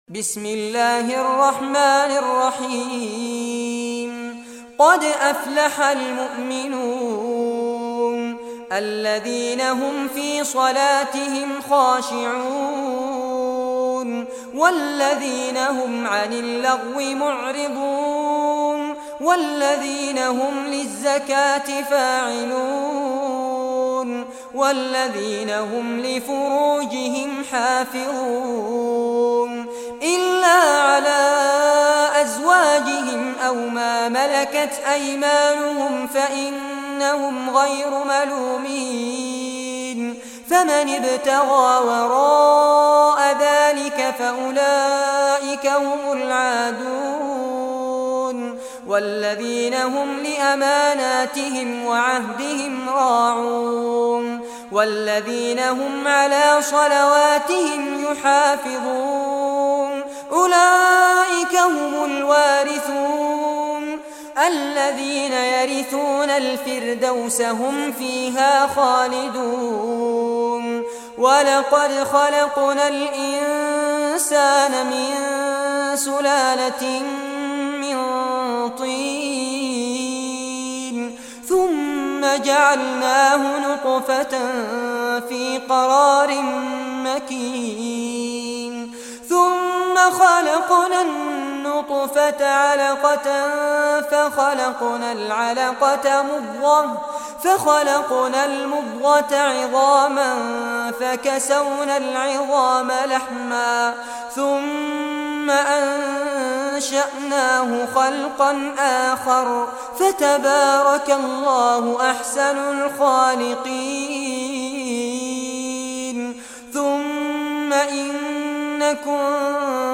Surah Al-Muminun Recitation by Sheikh Fares Abbad
Surah Al-Muminun, listen or play online mp3 tilawat / recitation in Arabic in the beautiful voice of Sheikh Fares Abbad.